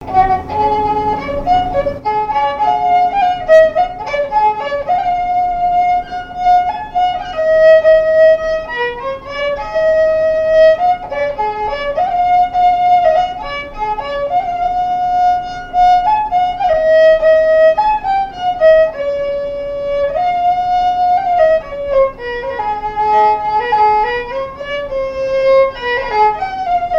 Beauvoir-sur-Mer
danse : aéroplane
Répertoire de marches de noce et de danse
Pièce musicale inédite